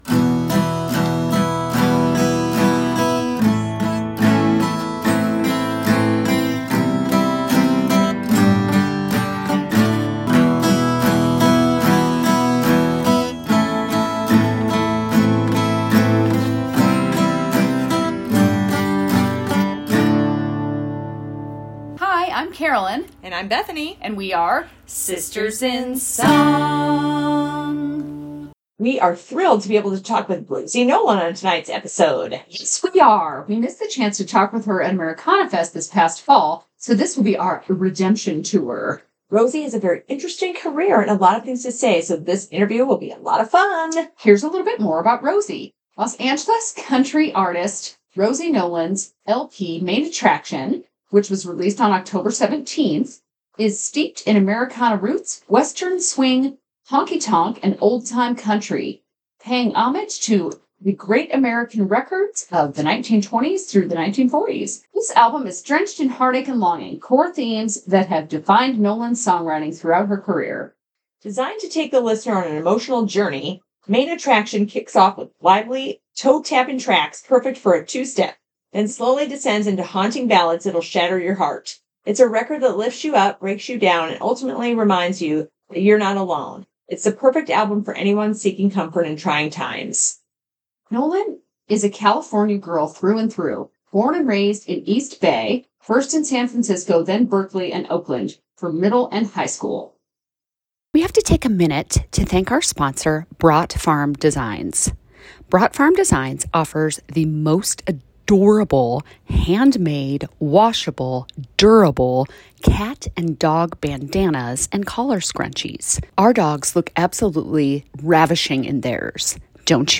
We'll take you on our journey to discovering our inner songwriters. We are two crazy sisters obsessed with what makes a good song tick.